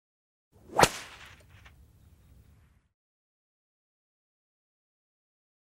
Вы можете слушать и скачивать резкие щелчки, свистящие удары в воздухе, отчетливые хлопки по поверхности.
Легкий хлопок кнута